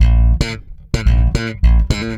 -AL DISCO B.wav